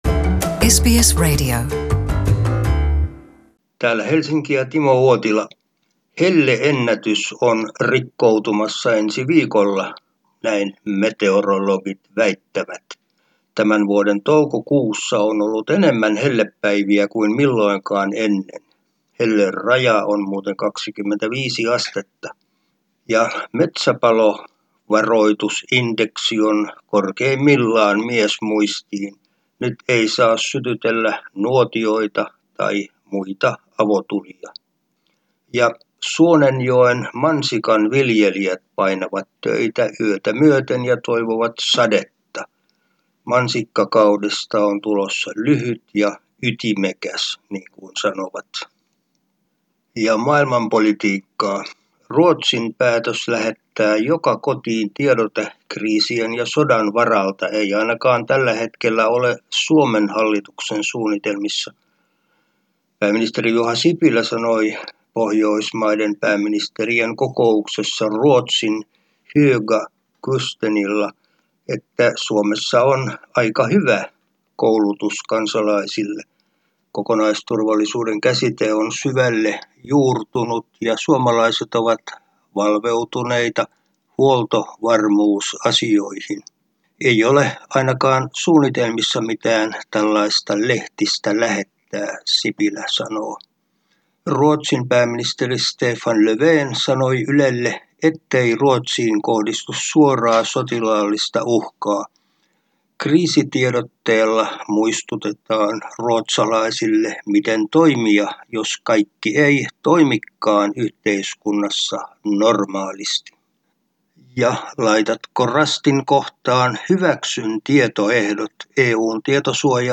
ajankohtaisraportti Suomesta